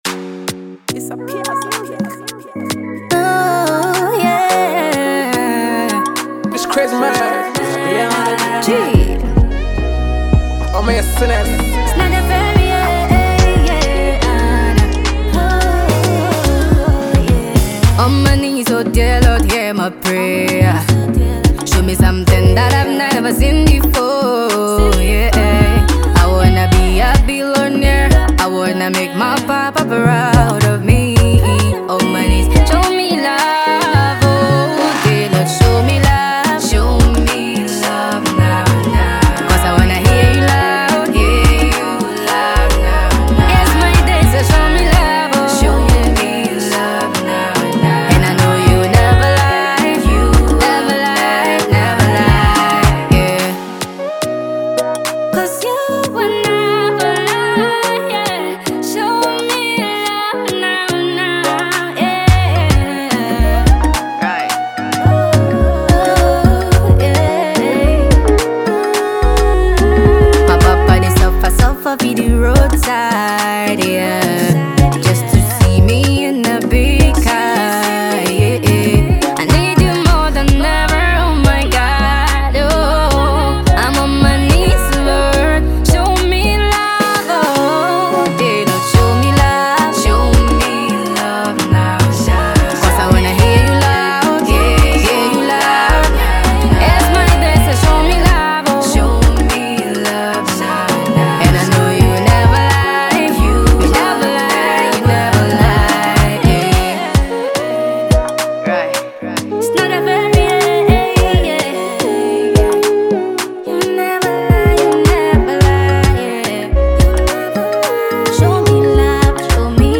an eclectic blend of genres
soulful voice